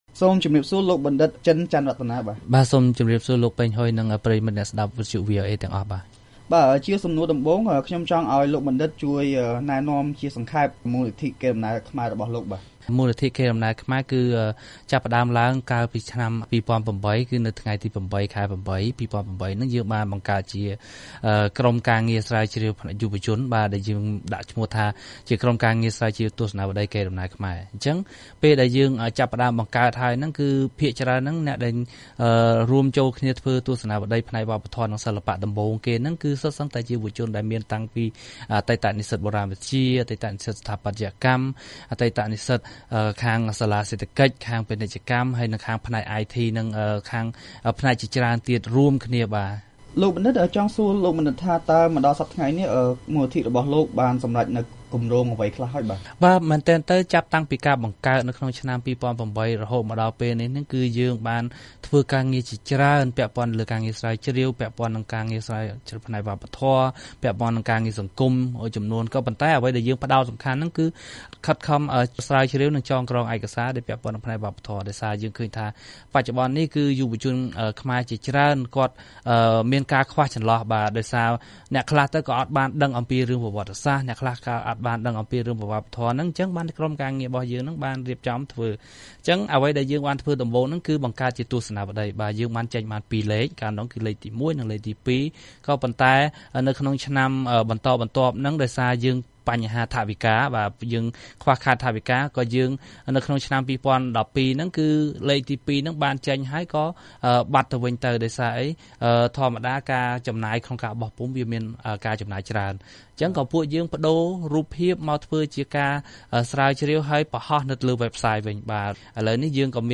បទសម្ភាសន៍ VOA៖ អ្នកជំនាញបុរាណវិទ្យាថា យុវជនកាន់តែច្រើនឡើងបាននាំគ្នាចូលរួមថែរក្សាវប្បធម៌ខ្មែរ
បទសម្ភាសន៍